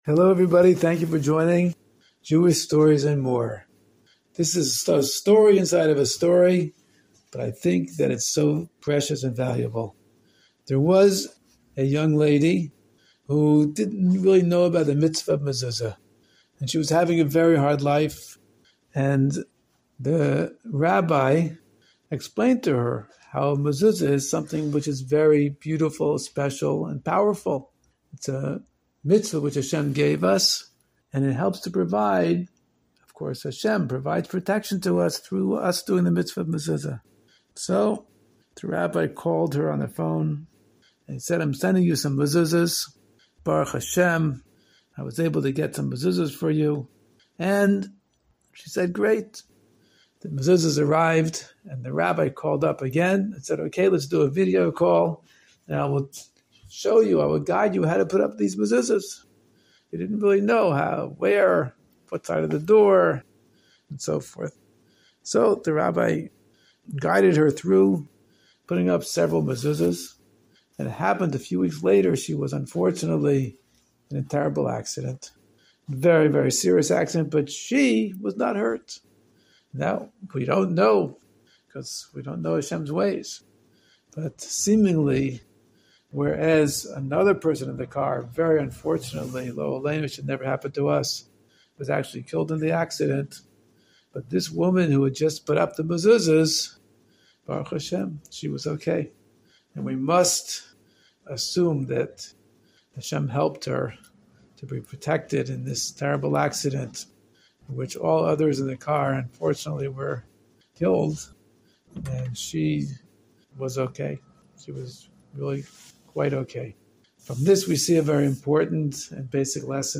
Story time for kids